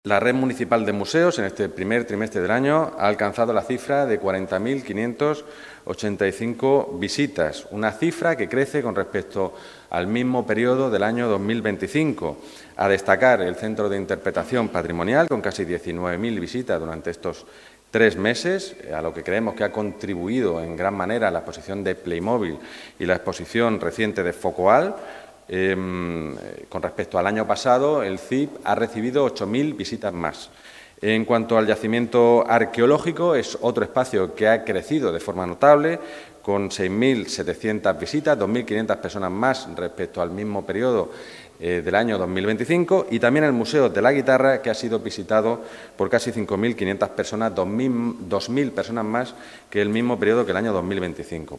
JOAQUIN-PEREZ-DE-LA-BLANCA-CONCEJAL-TURISMO-BALANCE-PRIMER-TRIMESTRE-2026.mp3